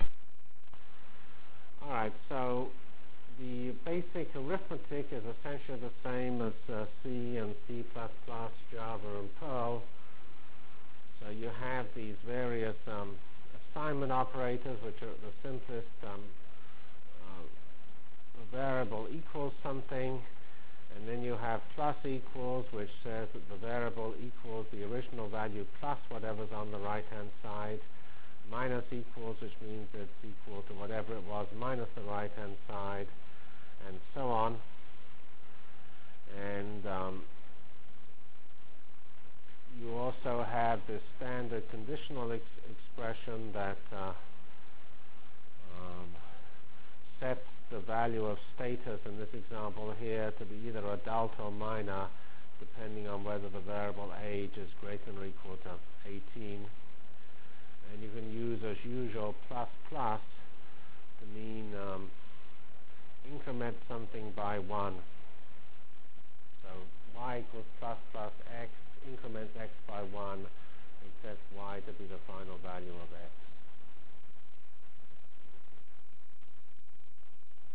Delivered Lecture